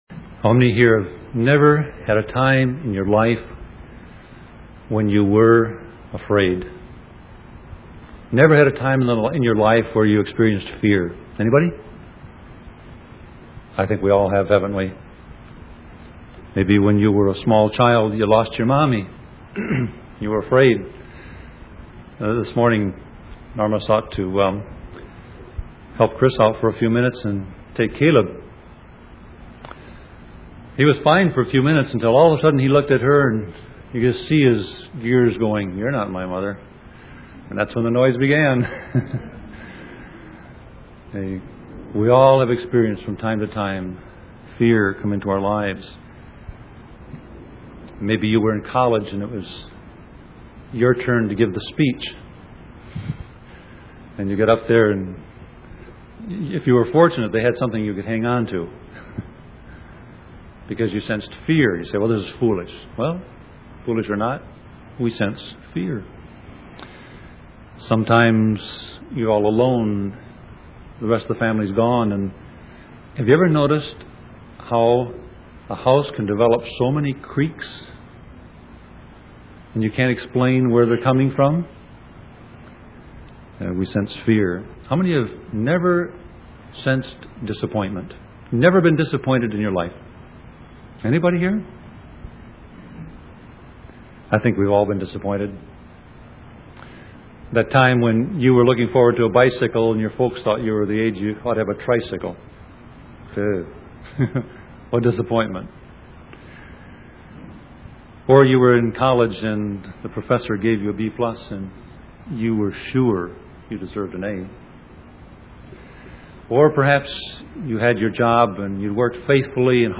Series: Sermon Audio